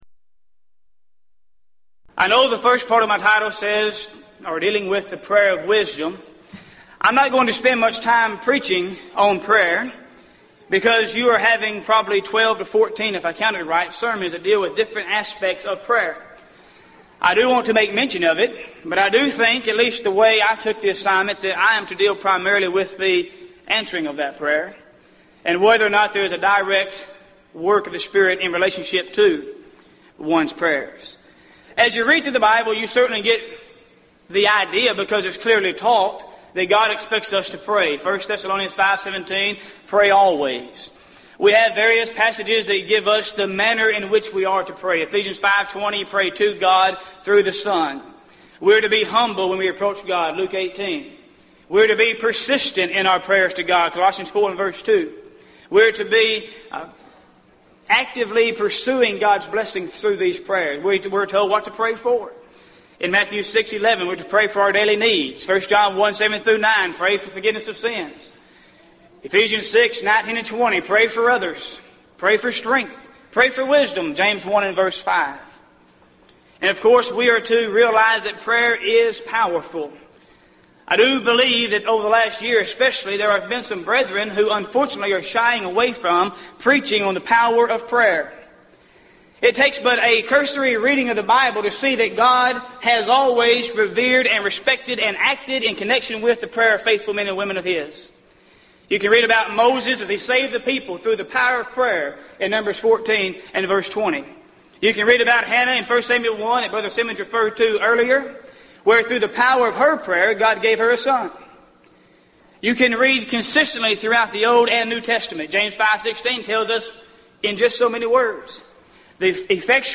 Event: 1998 Gulf Coast Lectures
lecture